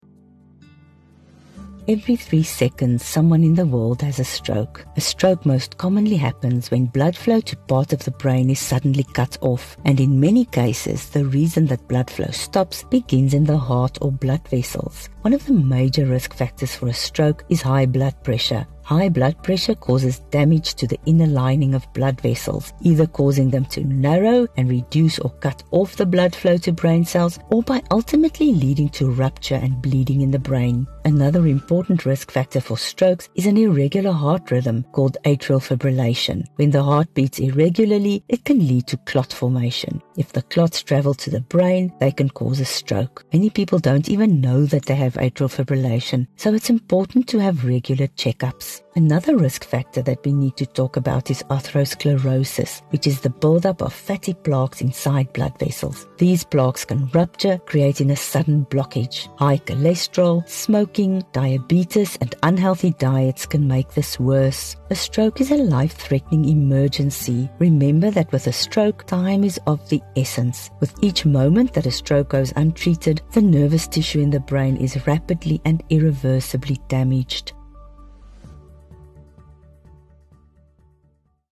Sharp-witted, informed, and fun! Familiar favourite and award-winning radio personality Elana Afrika-Bredenkamp makes sure that your workday remains productive by bringing a smile to your face every weekday from 09:00 to 12:00.